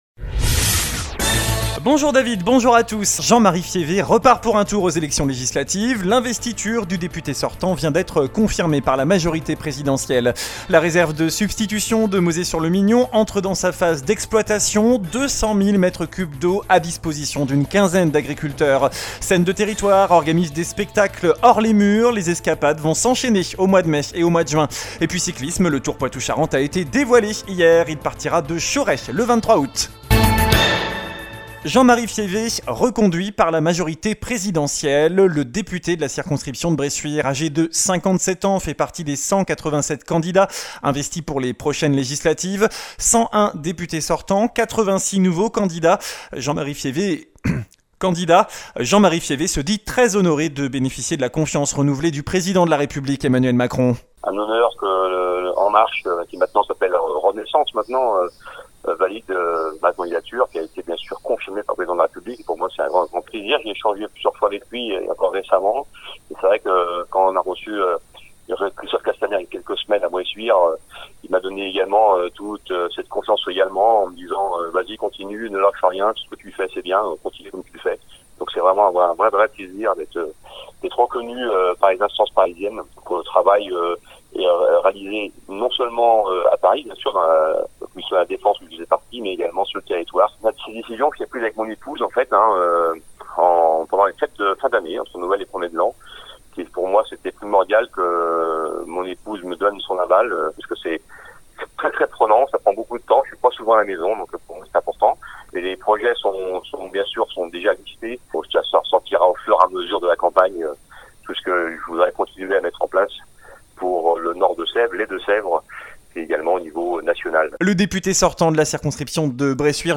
Journal du vendredi 6 mai (midi)